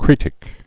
(krētĭk)